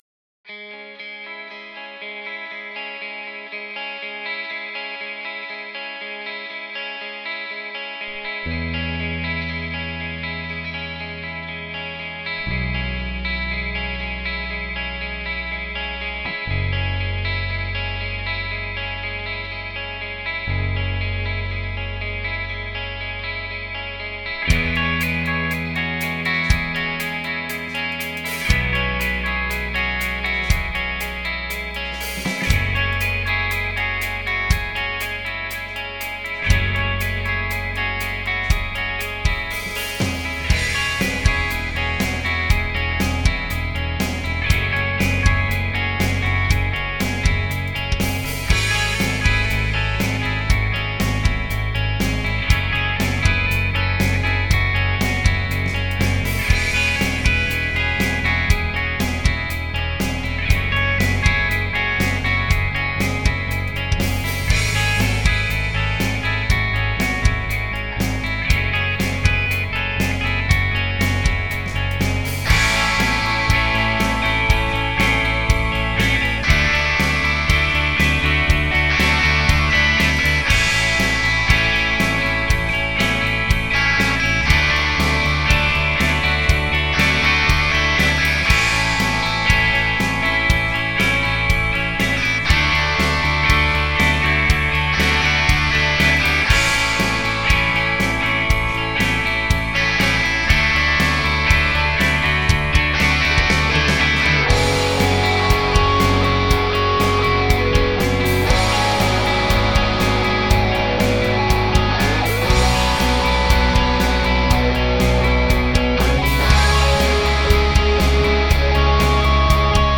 Had a mega cheesey afternoon today - this is totally unlike anything that I normally record. Loads of different tones in here - haven't quite worked out what I'm gonna go with it yet.